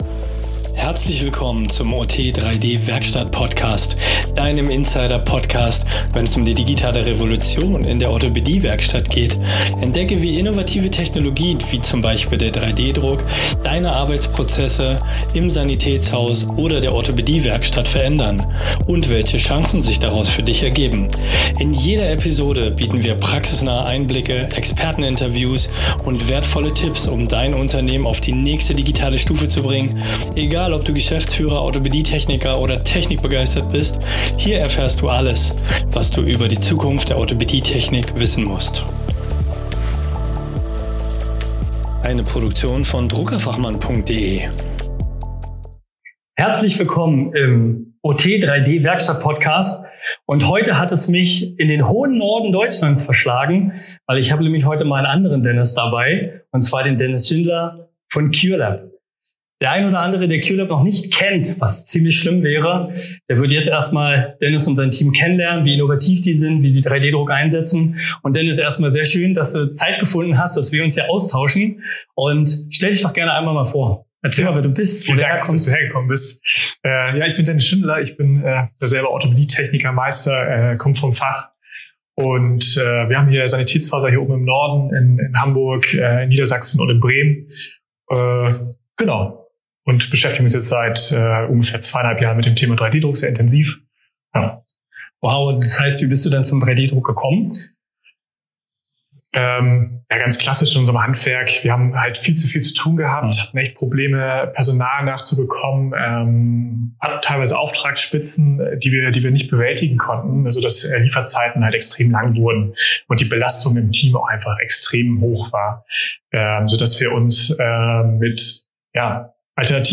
In dieser Folge erfährst du, wie digitale Modellierung, 3D-Druck und innovative Produktionsprozesse den Alltag von Orthopädietechnikern verändern. Wir sprechen mit den Experten von Cure Lab über digitale Workflows, effizientere Fertigungsprozesse und die Zukunft der patientenspezifischen Versorgung. Wie lassen sich Orthesen und Prothesen schneller, präziser und individueller fertigen?